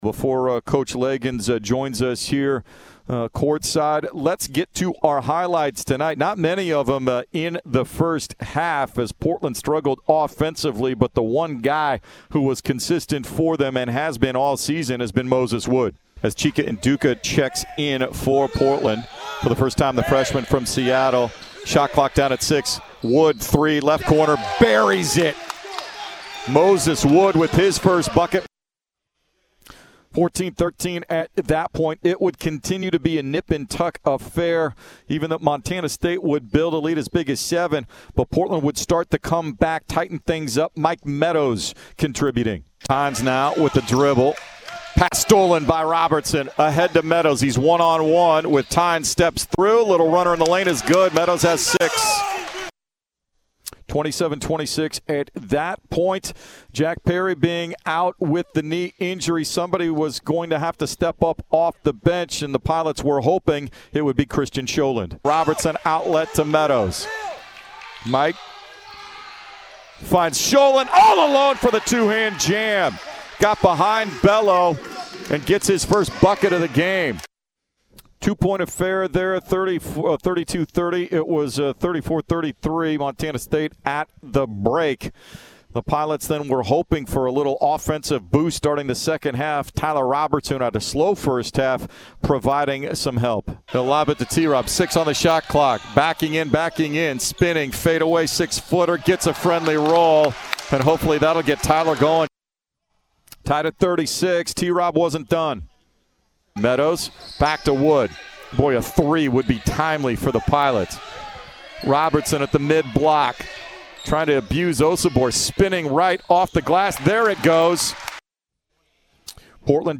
Men's Hoops Radio Highlights vs. Montana State
HIGHLIGHT_PACKAGE__1_.mp3